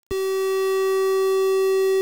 triangle_pitch_bad.wav